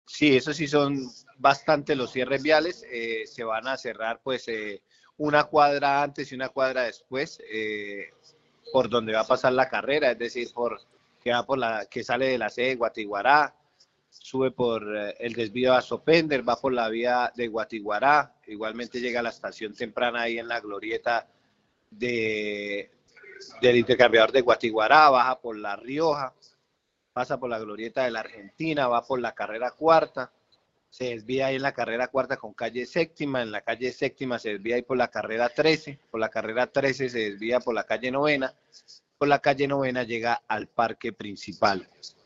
Adan Gelvez, secretario de Tránsito y Movilidad de Piedecuesta